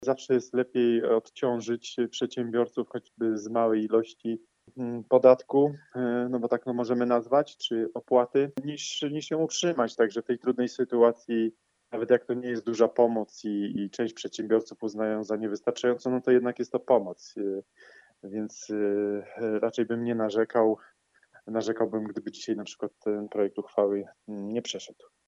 – Cieszy każda pomoc jaką możemy zaoferować gorzowskim przedsiębiorcom – dodaje Sebastian Pieńkowski, wiceprzewodniczący Rady Miasta z klubu Prawa i Sprawiedliwości: